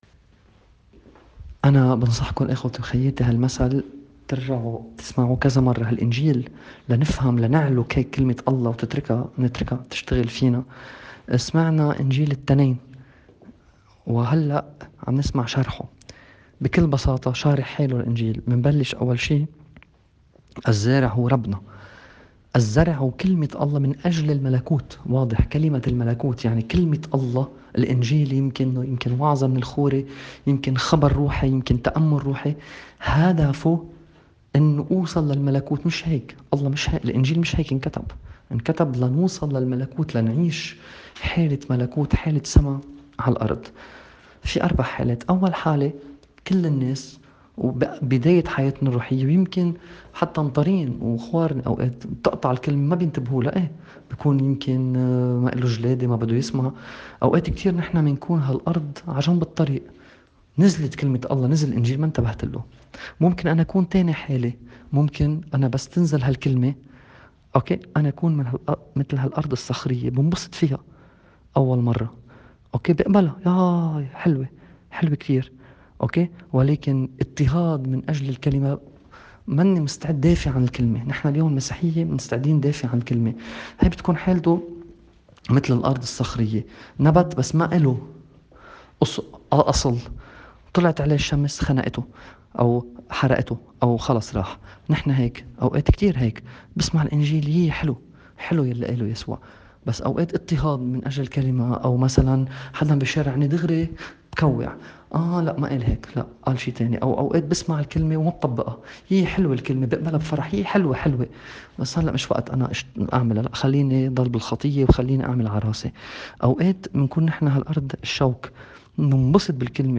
تأمّل في إنجيل اليوم
تأمّل في إنجيل يوم ٢٨ تشرين الأول ٢٠٢٠.mp3